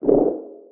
Sfx_creature_penguin_waddle_voice_07.ogg